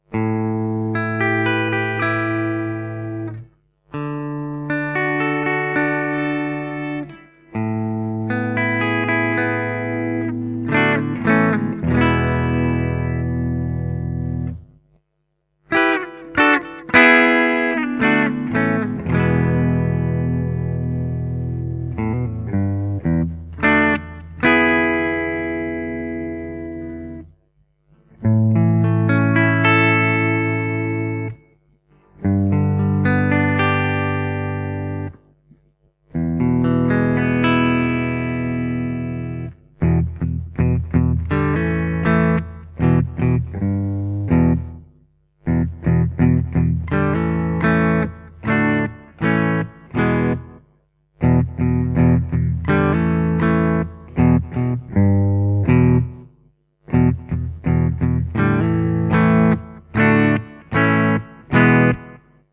The Pegasus is a raw vintage humbucker that wants to rock. It has an aggressive, edgy sound that is slightly darker, ideal for blues and classic rock to old metal.
Bridge Full      Bridge Tapped        Neck Full